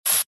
Звук перцового баллончика при распылении в лицо